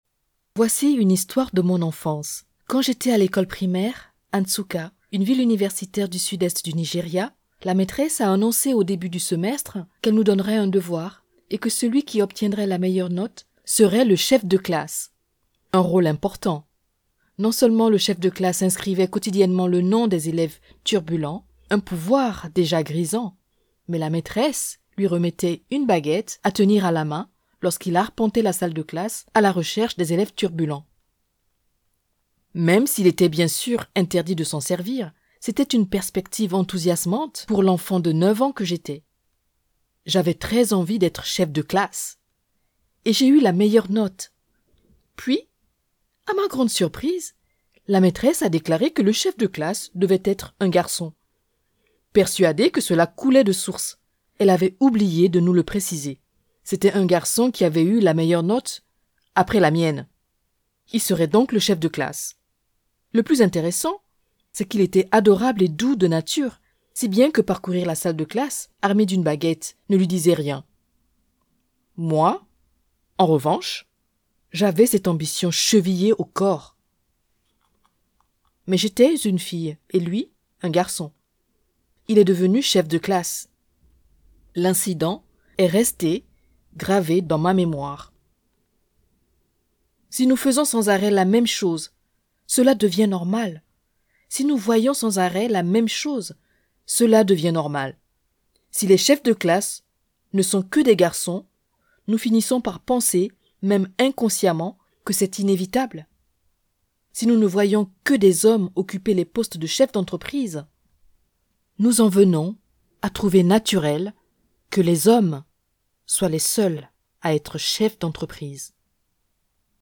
Audio Book Samples
Non-Fiction (French)
Believable